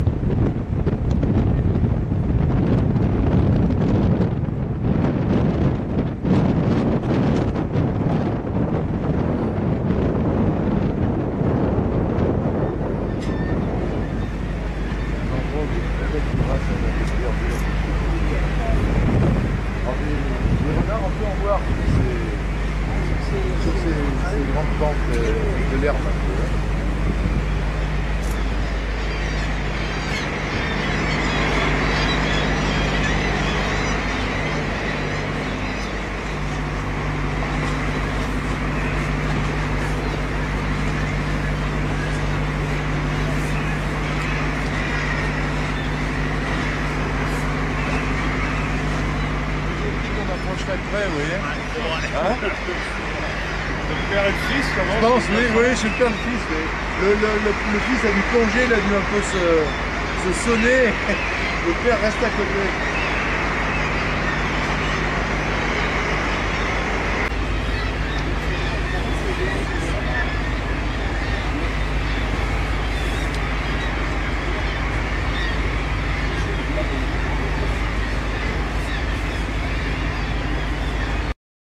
Guillmots de Brunnick